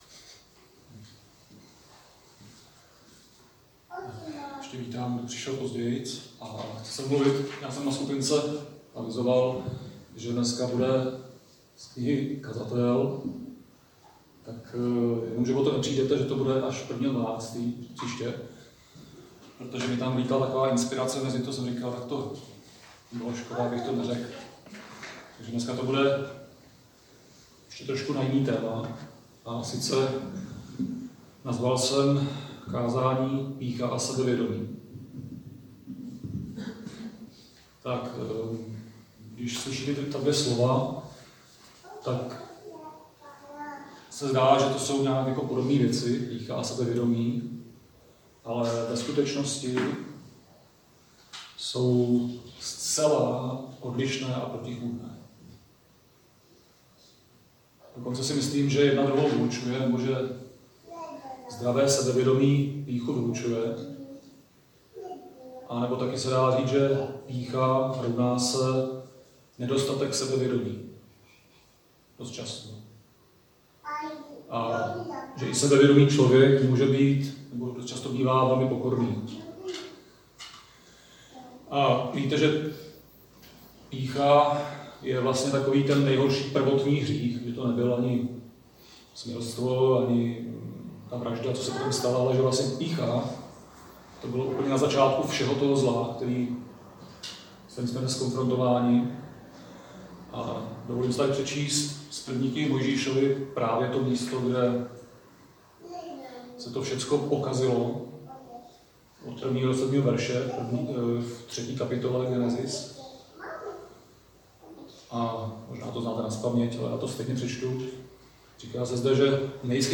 Křesťanské společenství Jičín - Kázání 17.11.2019